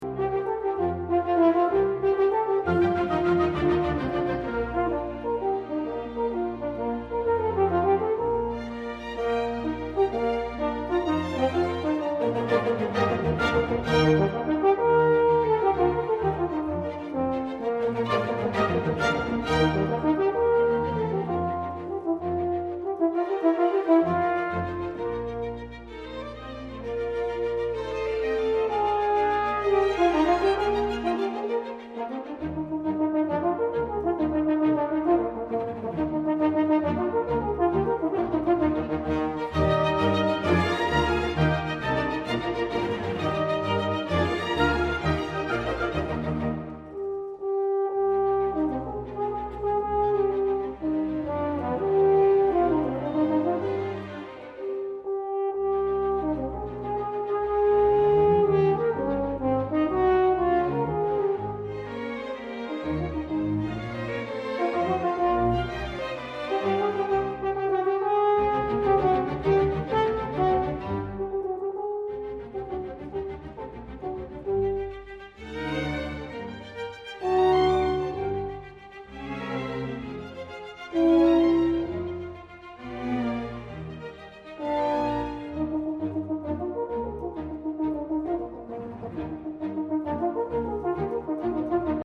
Recorded Edinburgh, Scotland December 4-5, 1993